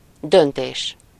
Ääntäminen
Synonyymit résolution arbitrage Ääntäminen France: IPA: [de.si.zjɔ̃] Haettu sana löytyi näillä lähdekielillä: ranska Käännös Ääninäyte 1. határozat 2. döntés Suku: f .